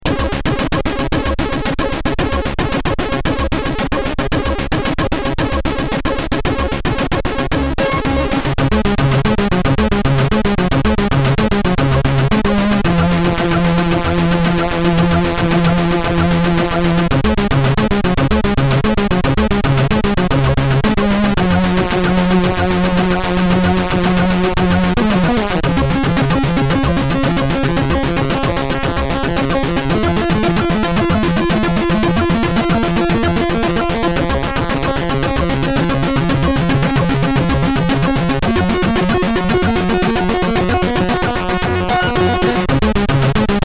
This is the theme music to the game.